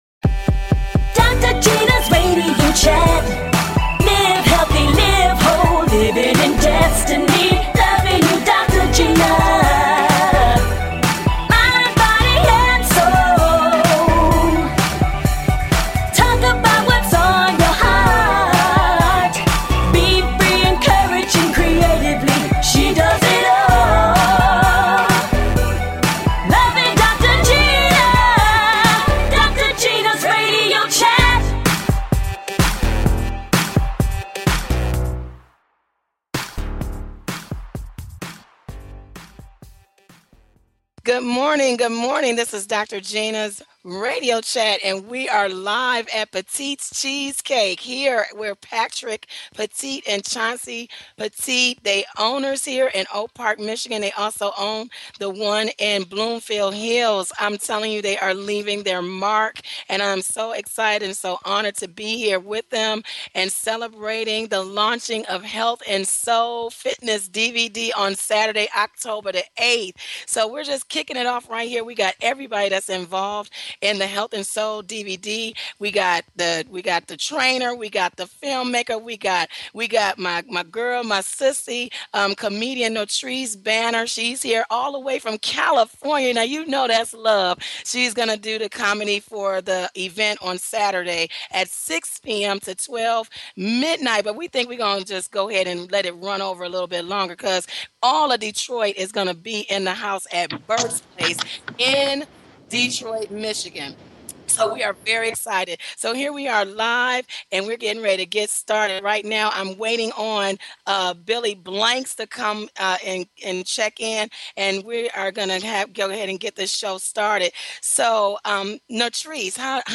Talk Show
Fun! Exciting! And full of laughter!